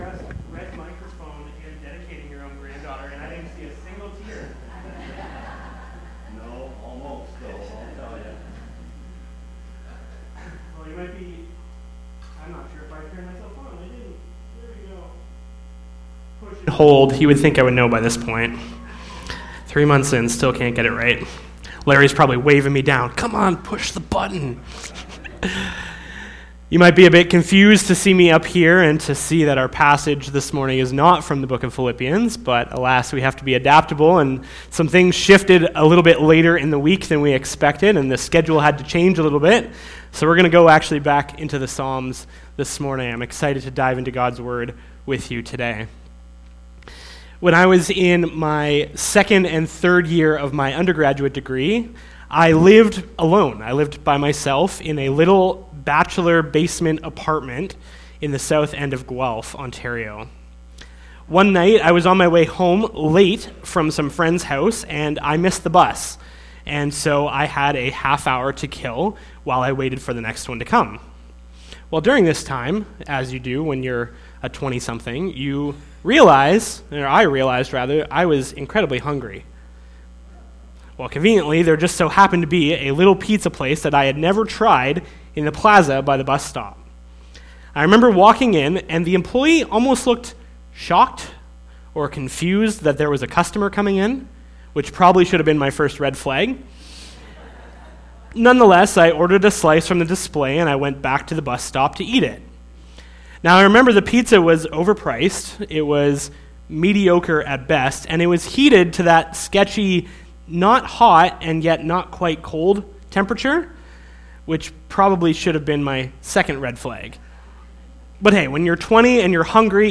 Sermon Audio